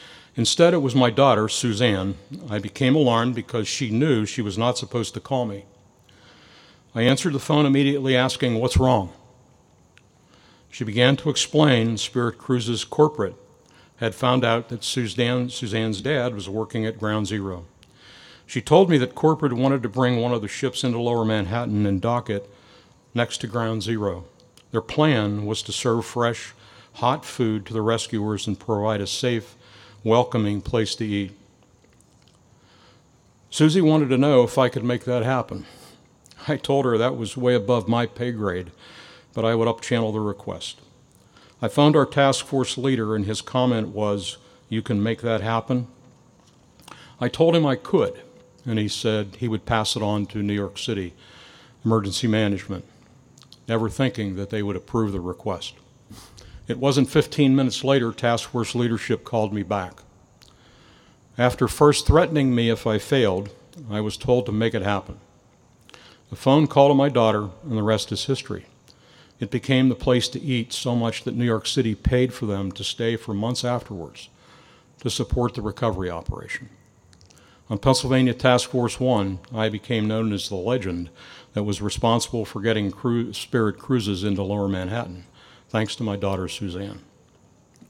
This morning, members of the IUP community gathered in the Oak Grove to pay tribute to the events of the September 11 attacks in New York, Washington D.C. and Shanksville.